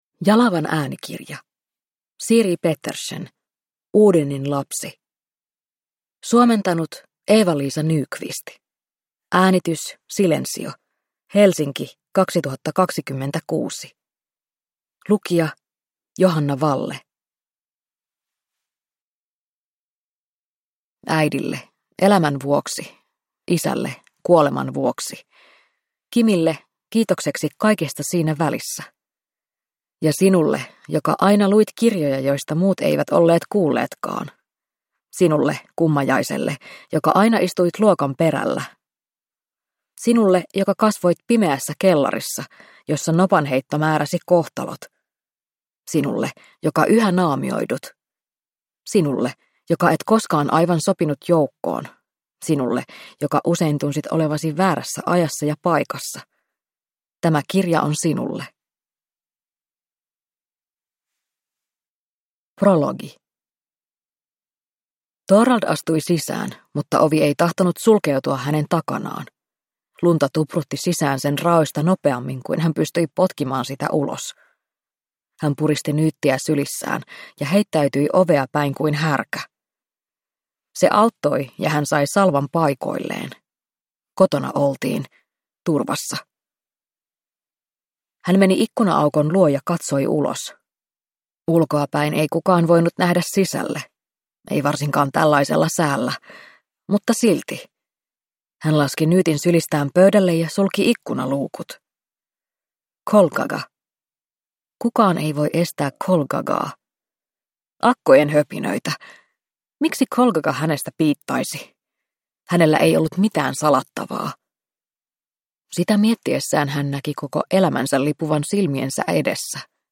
Odininlapsi – Ljudbok